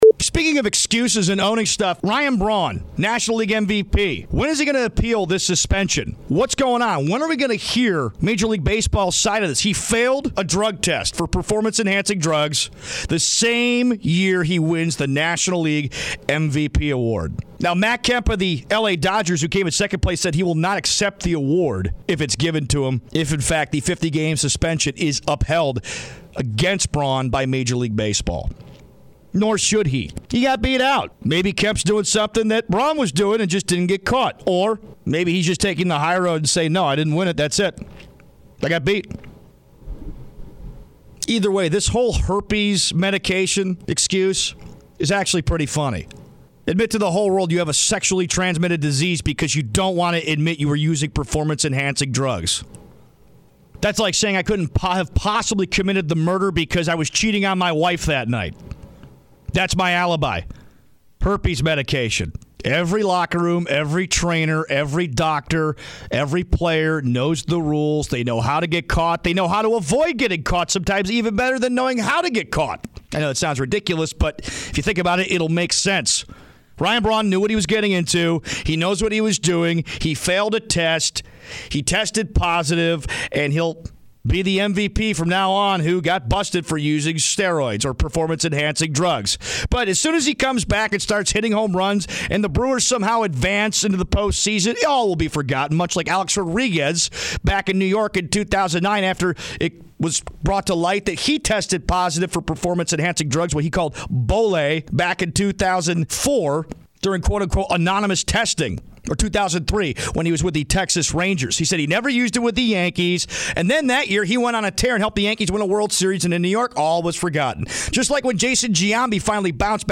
Category: Radio   Right: Personal